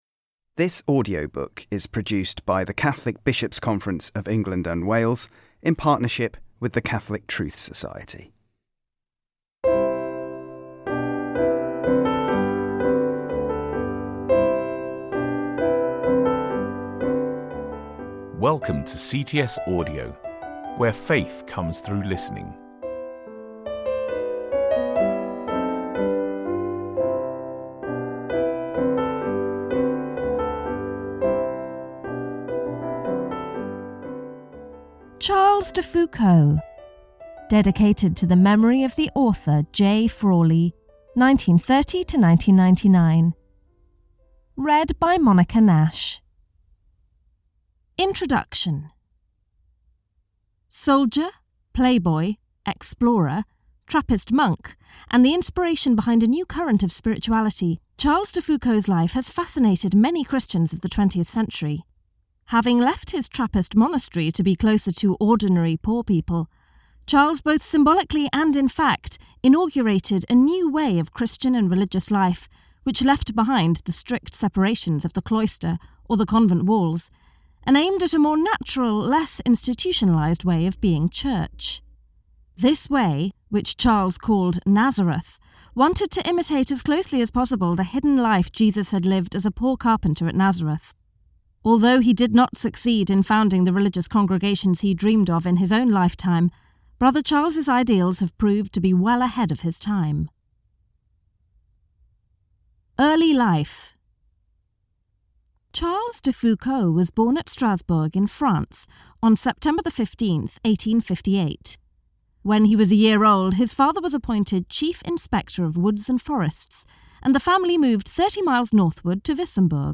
You can listen to this audiobook version for free.